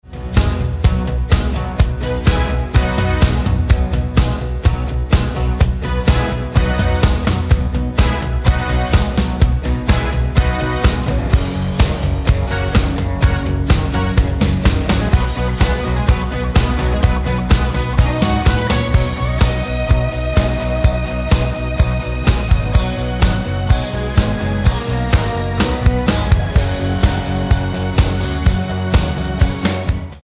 Tonart:B ohne Chor